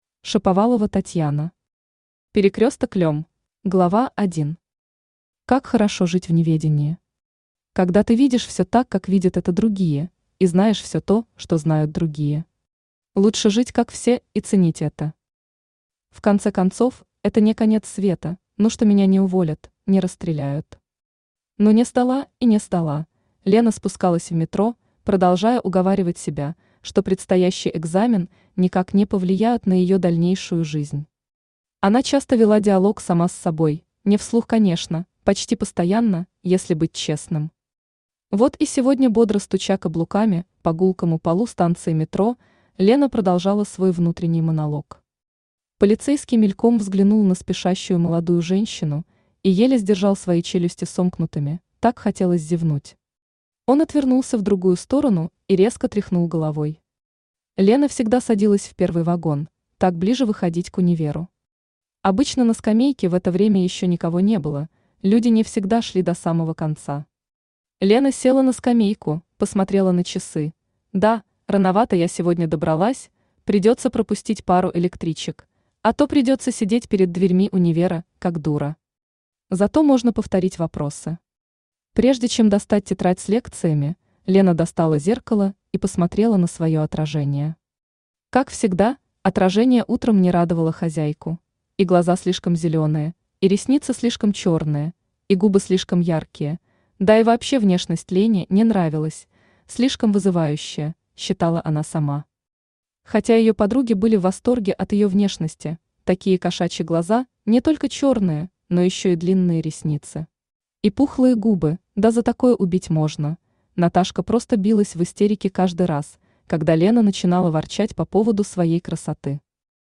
Аудиокнига Перекресток Лем | Библиотека аудиокниг
Aудиокнига Перекресток Лем Автор Шаповалова Татьяна Читает аудиокнигу Авточтец ЛитРес.